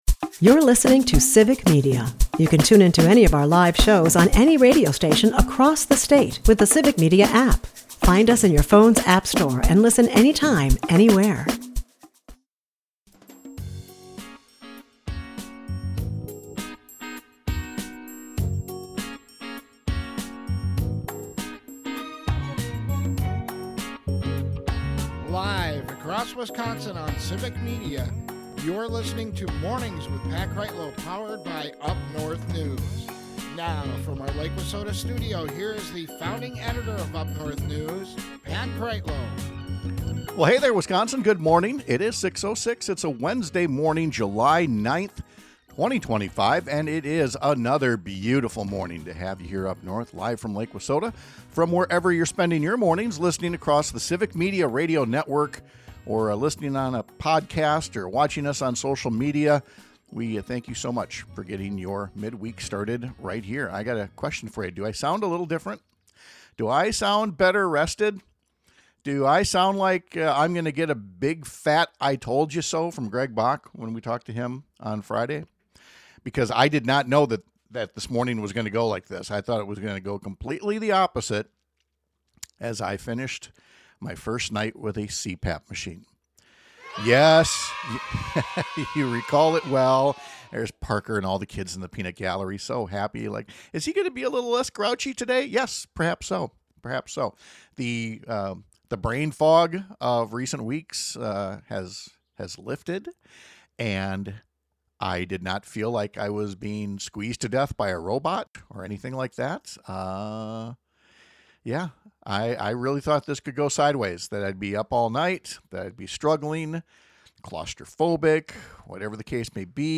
Our humble editor and host, a former state senator, gives an explanation of the legislature’s Joint Committee for the Review of Administrative Rules (JCRAR) and how the state Supreme Court on Tuesday called out GOP lawmakers for misusing it.